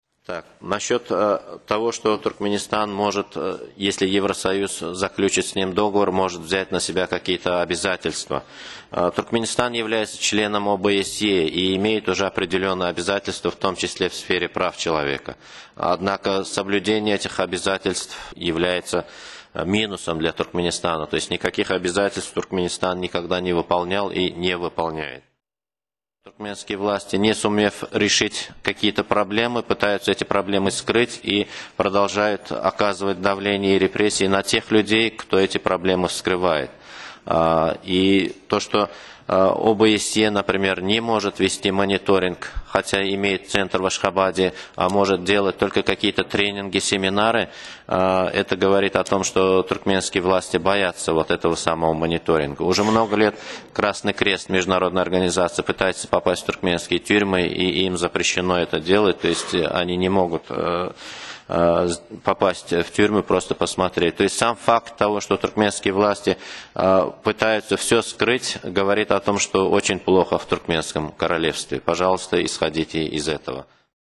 deňlenişikdäki çykyşyndan bölekler (20-nji aprel, Brýussel)